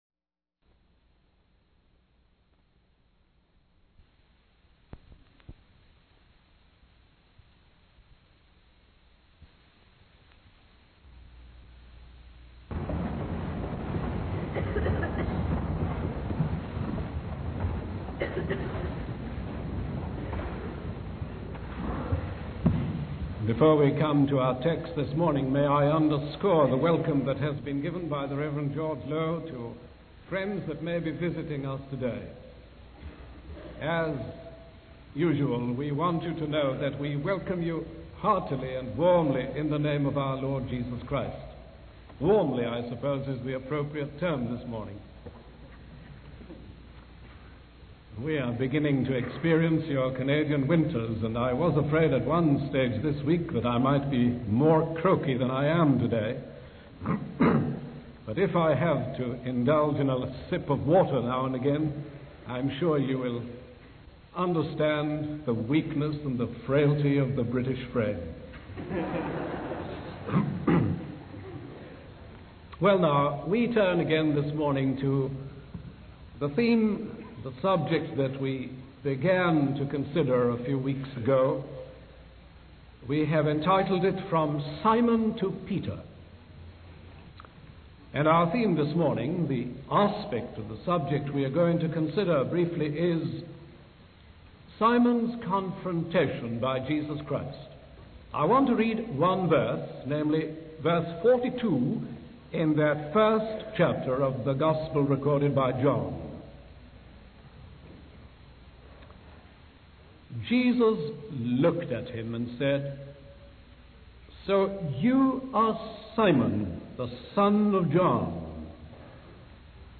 In this sermon, the preacher focuses on the story of Simon, also known as Peter, and how he was brought to Jesus by John the Baptist and his own brother Andrew.